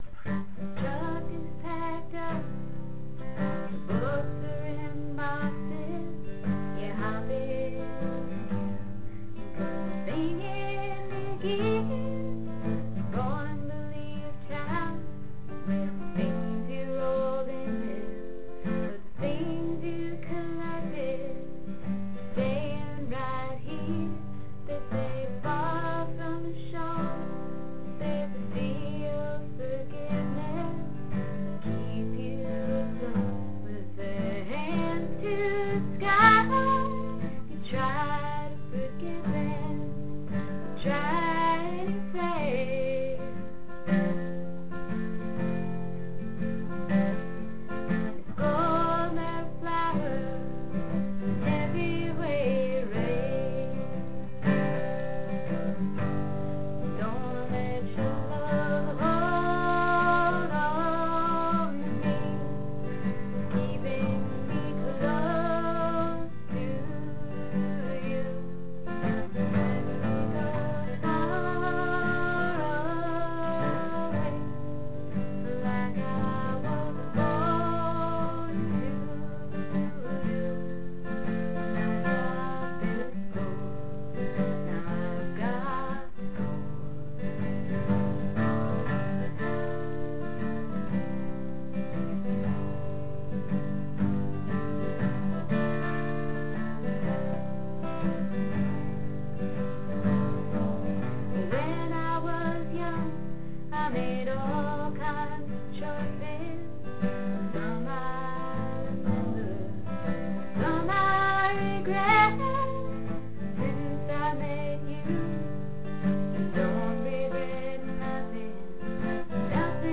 C G a C a G F C G C G a C a G C F C G C (G) d C G a G F G d C G a G F G F G d C G d C G